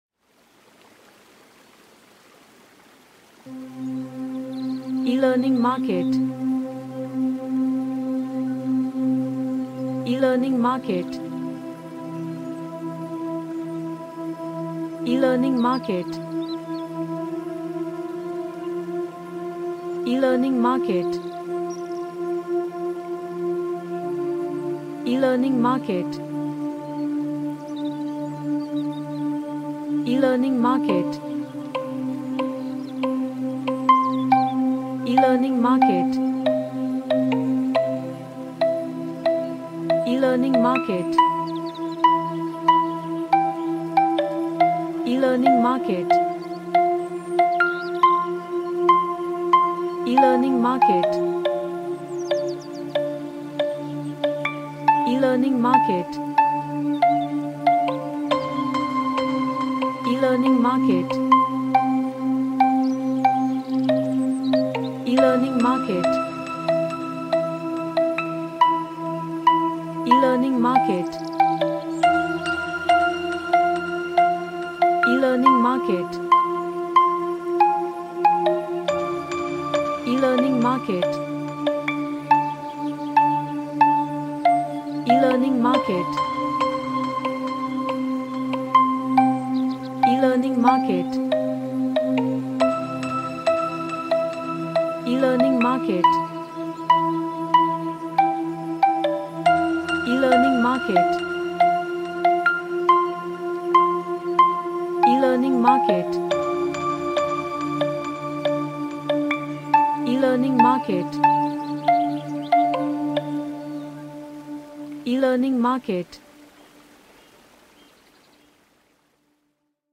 A smooth ambient track
Relaxation / Meditation